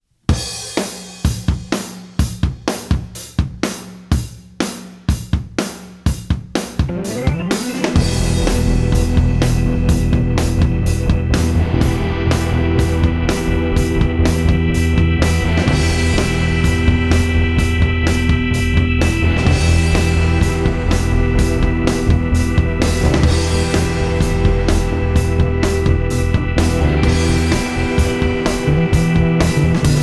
Bass, Keyboards, and Harmony Vocals